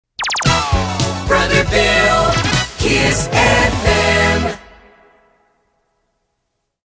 Jock ID